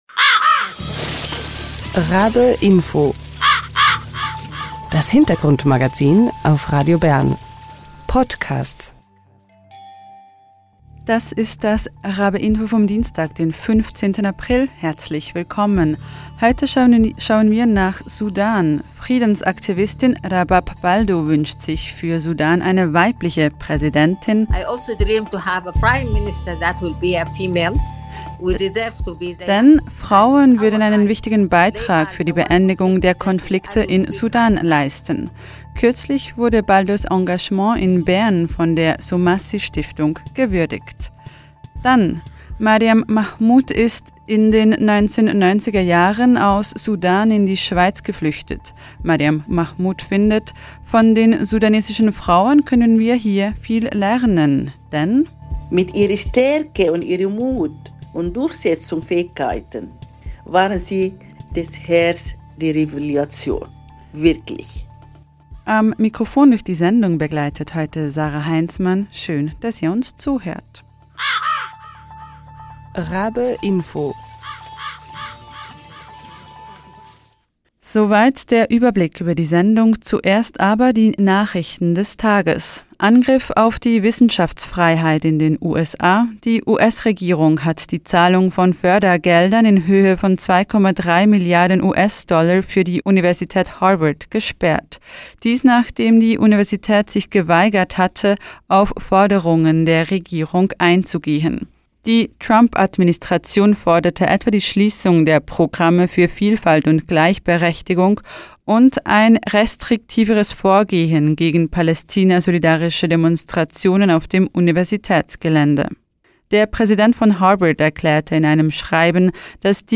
Beschreibung vor 1 Jahr Heute jährt sich der Beginn des Konflikts zwischen den paramilitärischen Rapid Support Forces und der sudanesischen Armee zum zweiten Mal. Im «Info» sprechen wir mit zwei Frauen, die sich für mehr Frieden in Sudan engagieren.